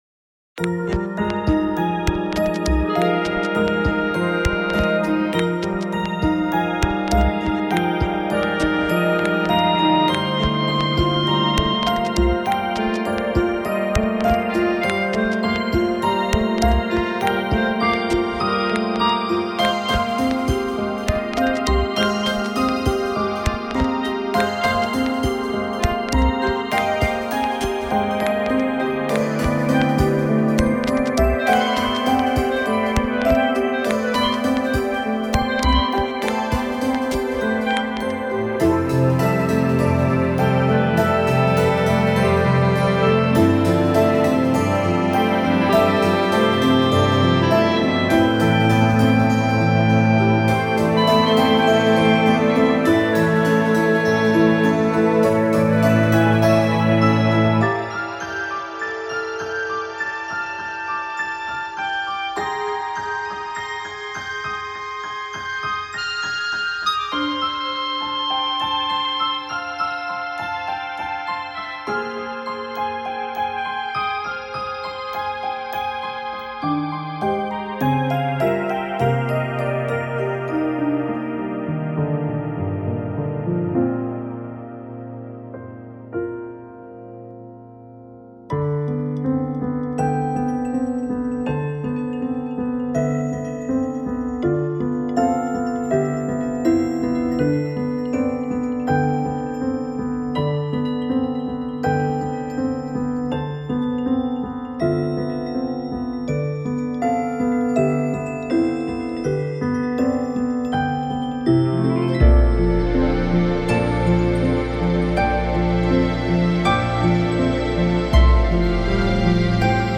hoàn toàn ko có vocal, cũng ko có cảm giác hào hùng